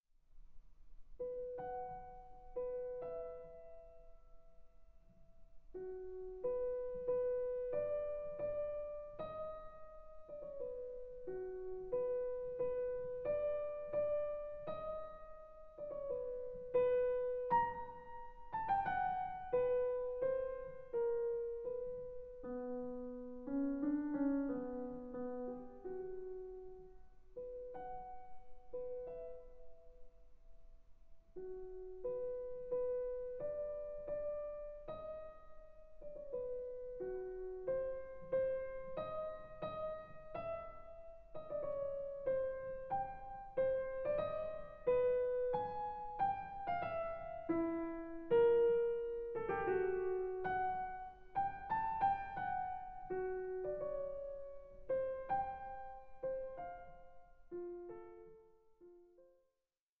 Largo 8:03
a work of stark introspection and formal ingenuity.